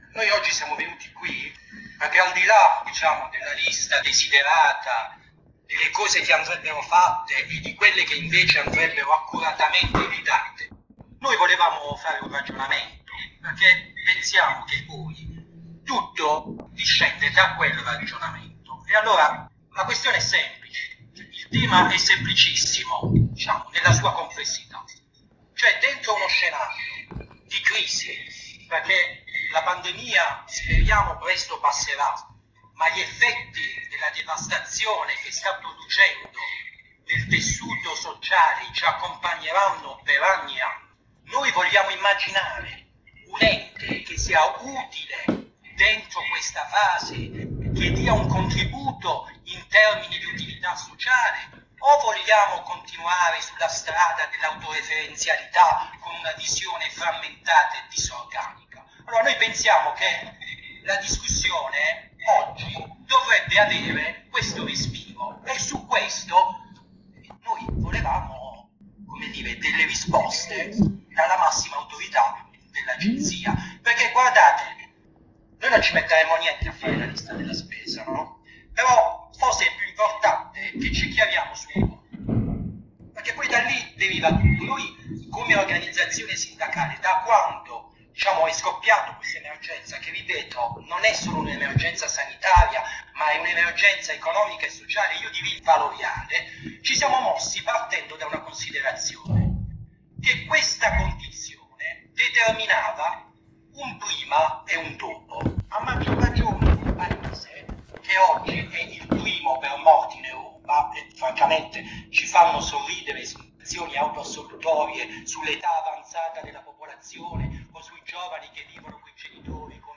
Pensavamo fosse un incontro con il Direttore dell'Agenzia delle Entrate ... AUDIO dell'intervento di USB